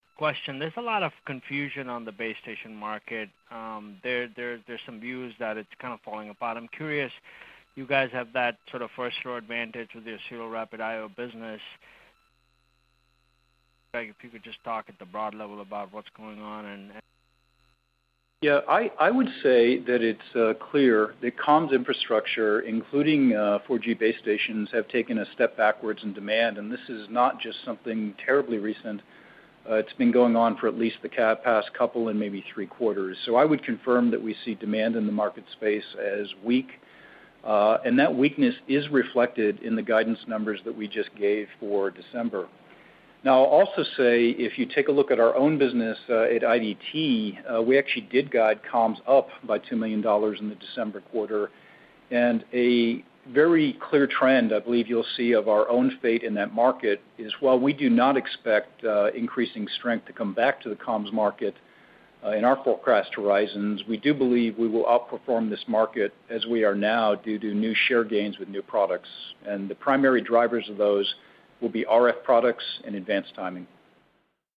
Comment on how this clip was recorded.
During IDT's Q2 fiscal 2017 earnings call